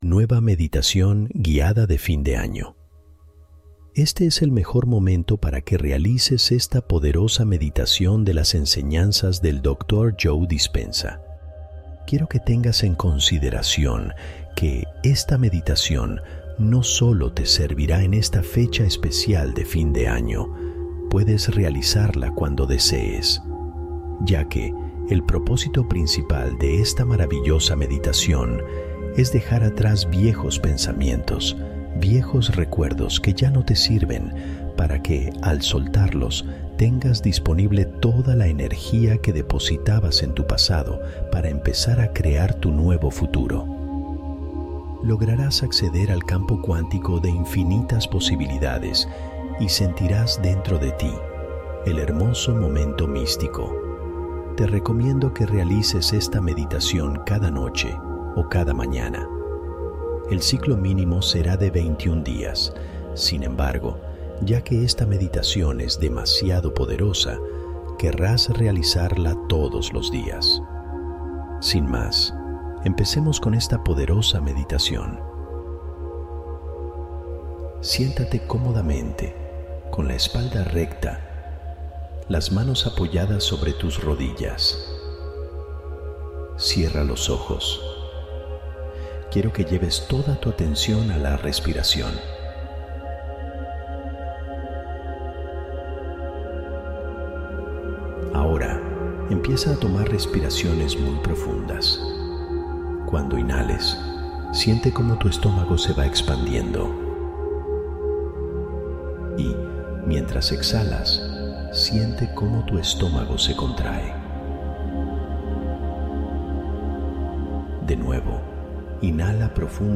Año nuevo interior: meditación para sembrar una intención vital